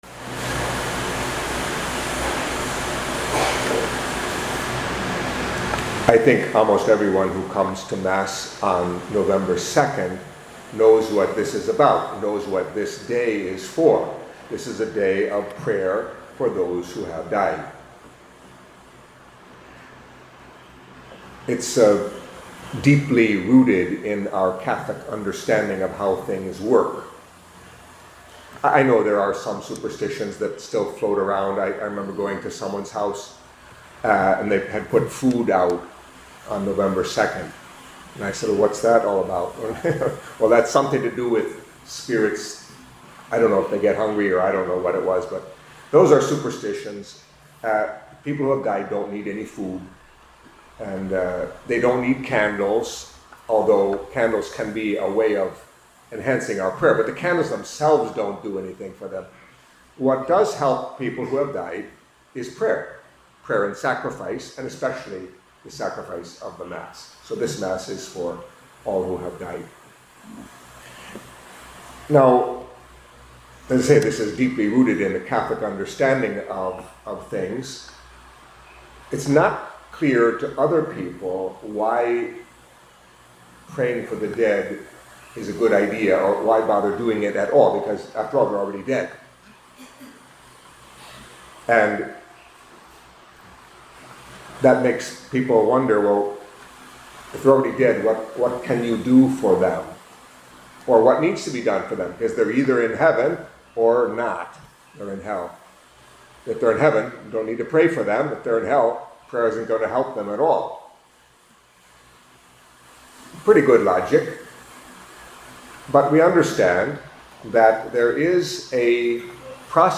Catholic Mass homily for the Commemoration of All the Faithful Departed